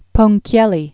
(pōng-kyĕllē), Amilcare 1834-1886.